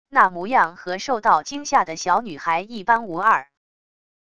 那模样和受到惊吓的小女孩一般无二wav音频生成系统WAV Audio Player